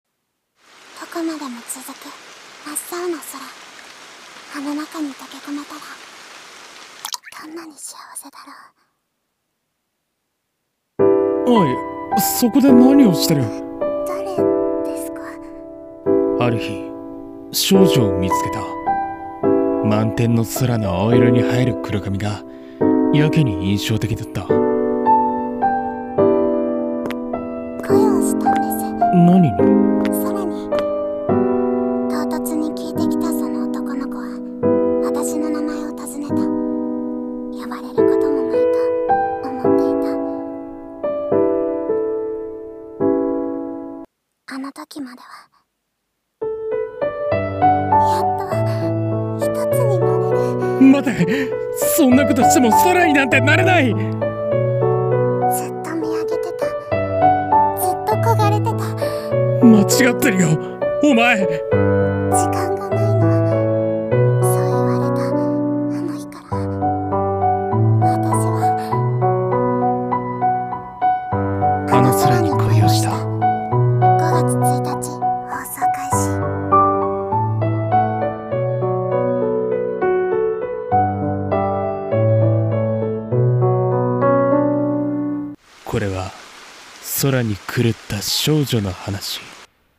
CM風声劇「あの空に恋をした」